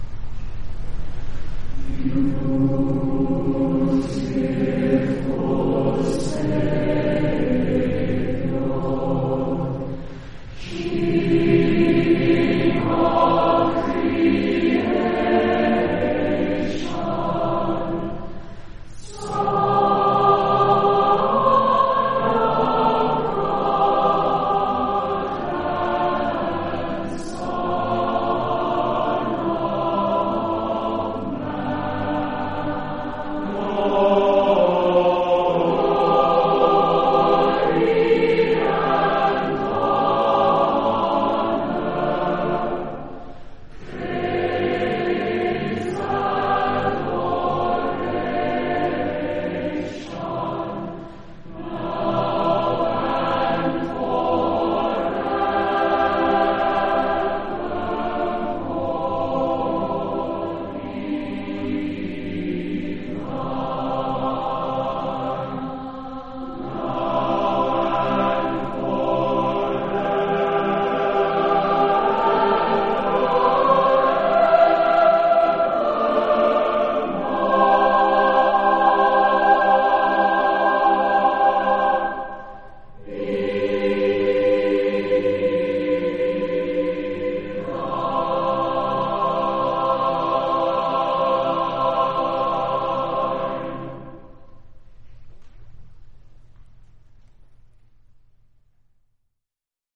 Silesian Folk Tune